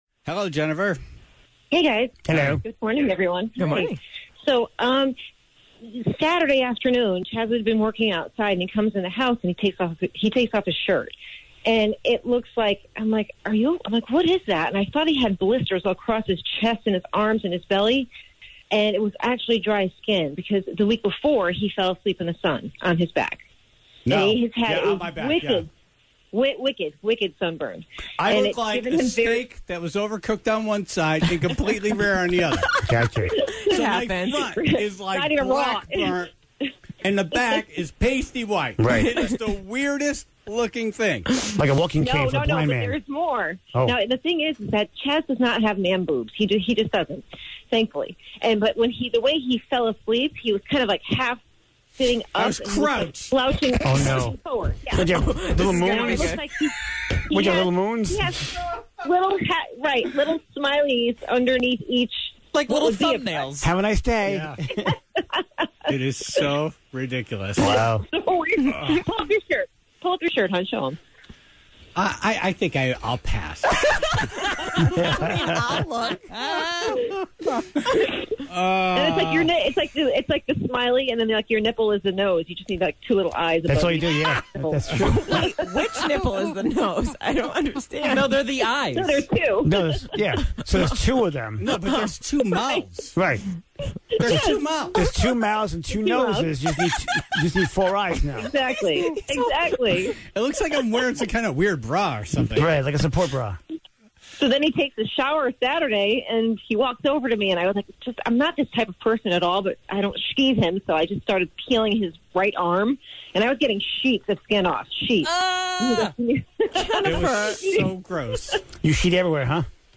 (0:00) The singing dogs of Instagram!